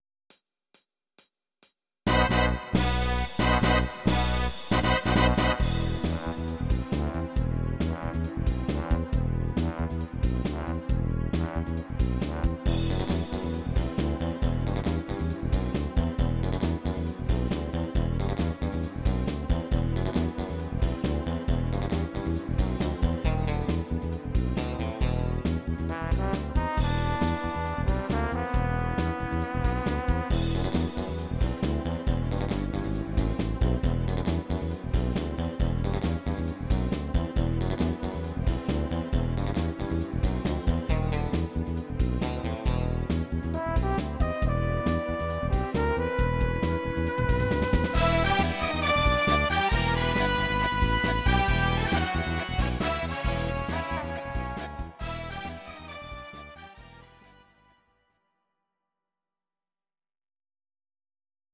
Audio Recordings based on Midi-files
Musical/Film/TV, Instrumental